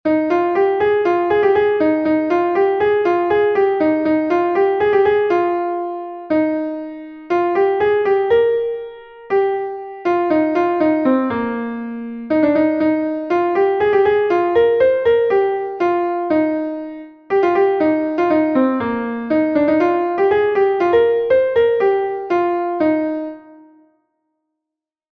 Ton Bale An Drinded-Langonned is a Bale from Brittany